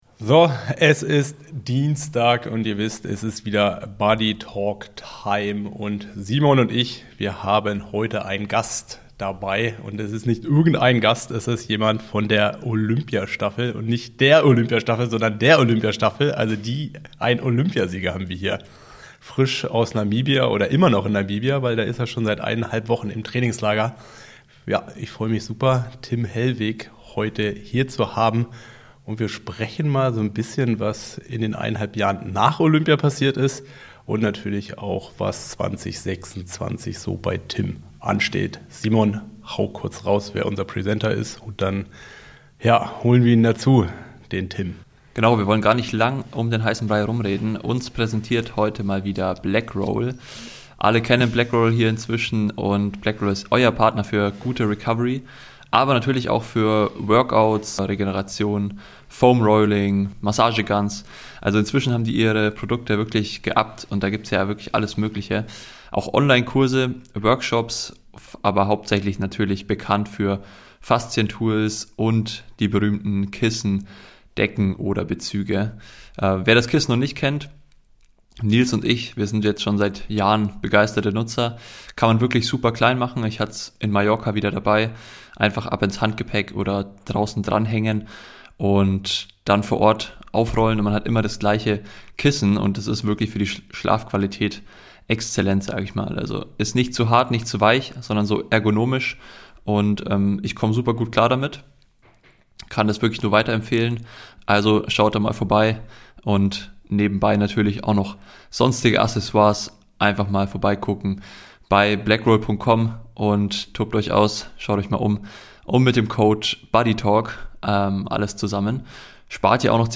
Wir haben Tim Hellwig zu Gast – Olympiasieger, Kämpfer und einer der spannendsten Athleten der deutschen Triathlon-Szene.